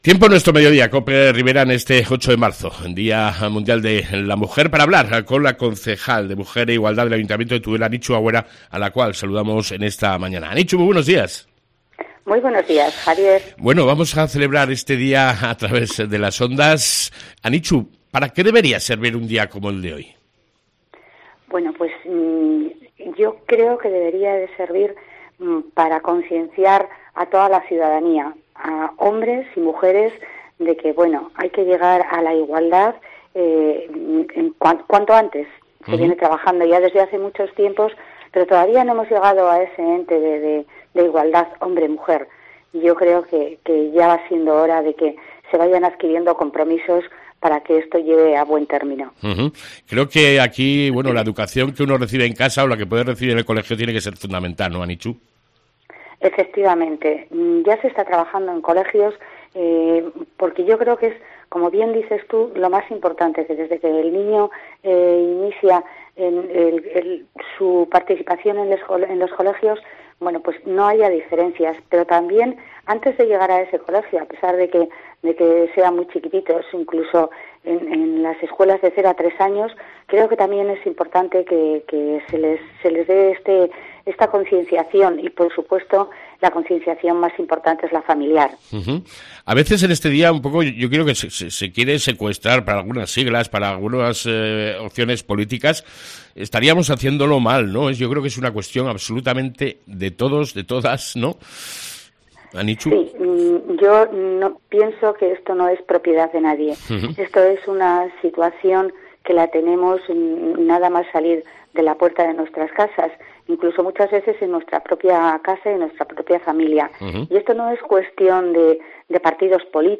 ENTREVISTA CON LA CONCEJAL DE MUJER E IGUALDAD ANICHU AGÜERA